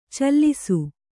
♪ callisu